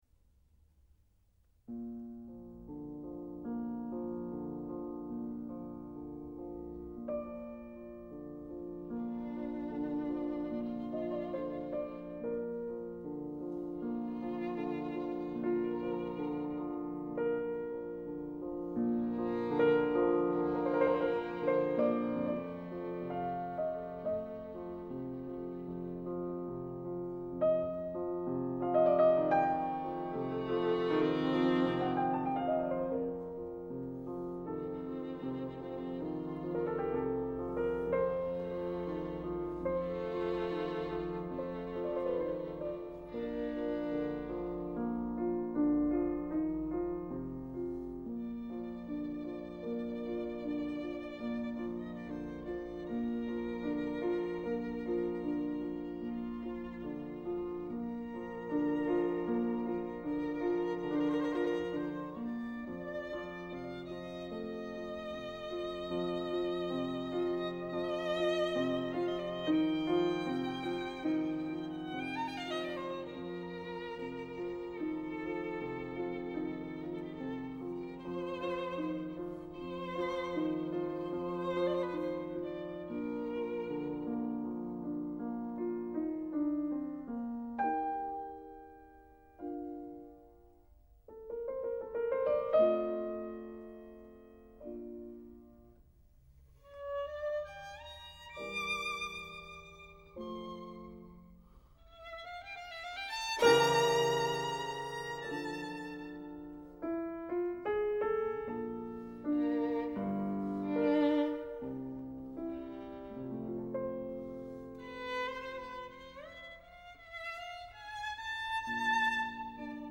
• Ludwig Van Beethoven – Sonate pour piano et violon n°5 « Le printemps », op.23
Martha Argerich, piano ; Gidon kremer, violon – 1987 *****
Cette cinquième sonate « Printemps », achevée en 1801, est l’une des plus connues et populaires de son corpus pour piano et violon, elle est d’une fraicheur ravissante et d’un accès très facile.